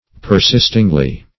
-- Per*sist"ing*ly , adv.
persistingly.mp3